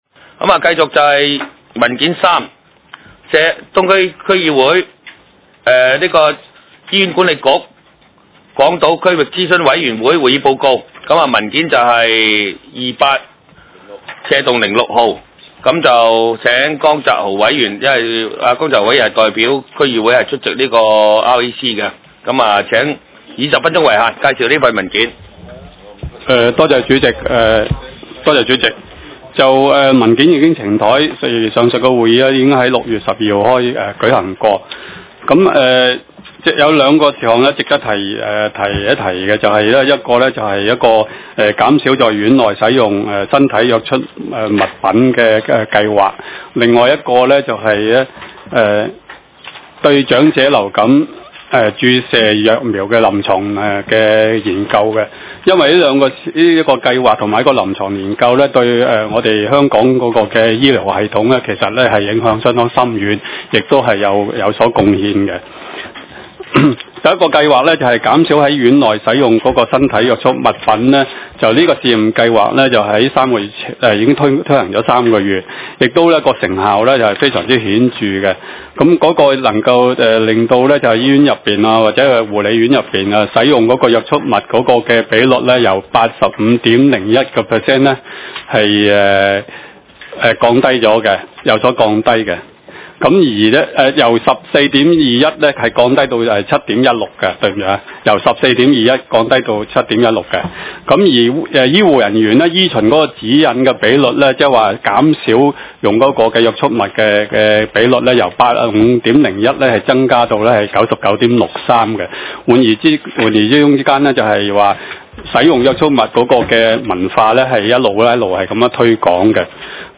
社區建設及服務委員會第四次會議
東區法院大樓11樓東區議會會議室